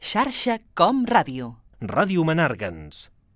Indicatiu emissora